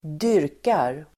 Uttal: [²d'yr:kar]